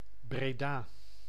Breda (/ˈbrdə/ BRAY-də, also UK: /ˈbrdə/ BREE-də, US: /brˈdɑː/ bray-DAH, Dutch: [breːˈdaː]